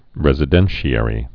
(rĕzĭ-dĕnshē-ĕrē, -shə-rē)